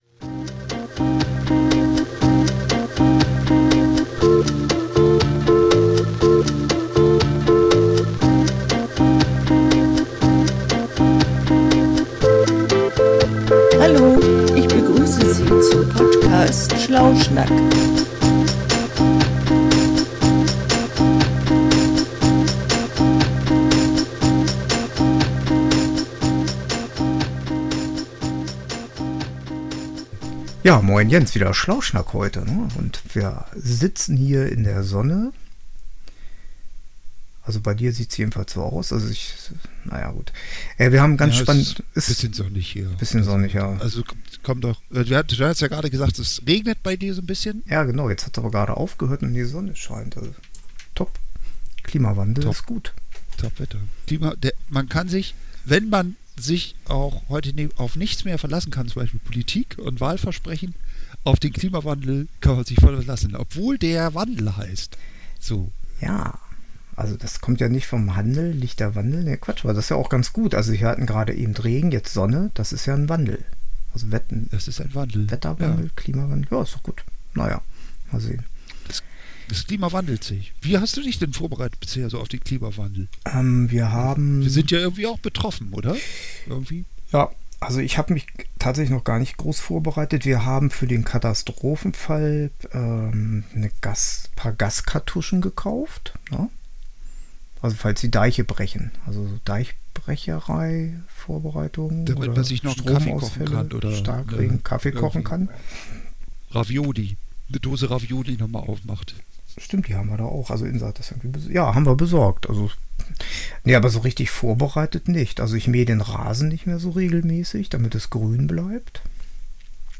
Aber wie schon öfter frei nach dem Moto: Hier gibt es nix zu sehen. 36 Minuten 36.62 MB Podcast Podcaster Schlauschnak Schlauschnak ist ein Podcast von zwei Schlauschnackern bzw. zwei, die sich für schlau halten.